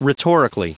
Prononciation audio / Fichier audio de RHETORICALLY en anglais
Prononciation du mot : rhetorically